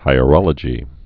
(hīə-rŏlə-jē, hī-rŏl-)